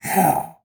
animal
Ocelot Hiss 1